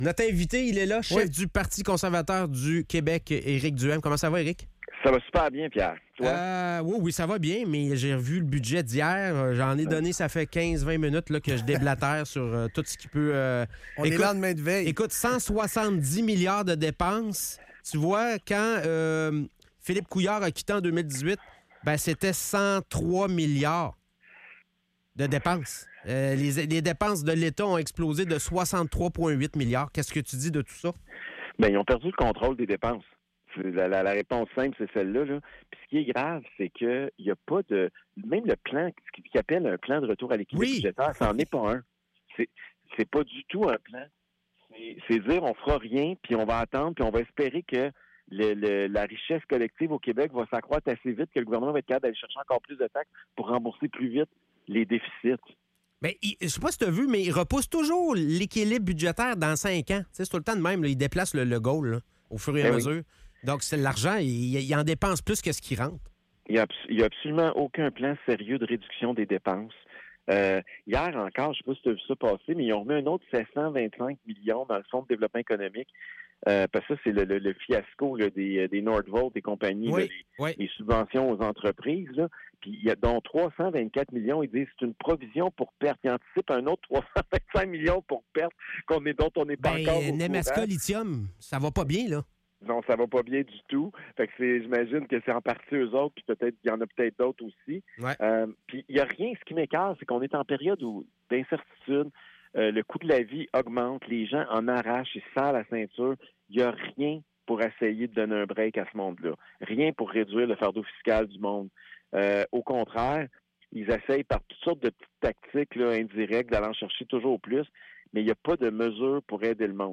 Entrevue avec Éric Duhaime, chef du PCQ